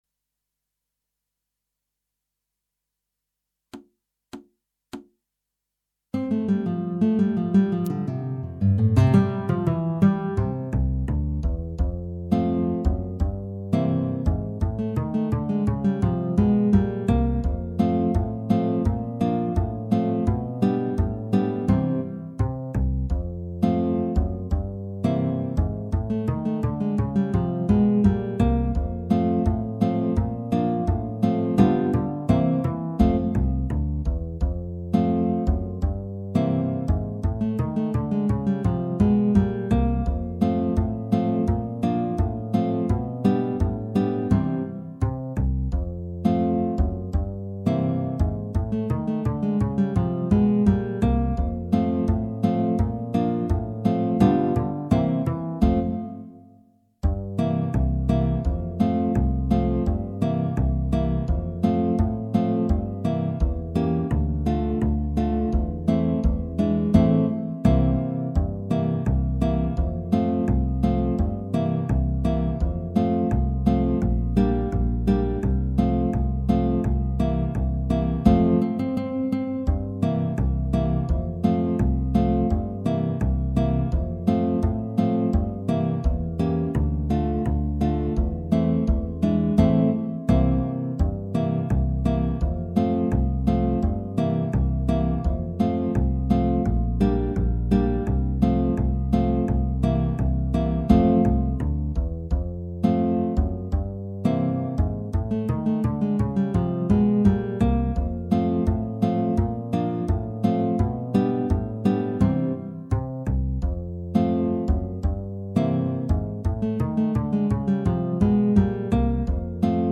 Guitar trio
minus Guitar 1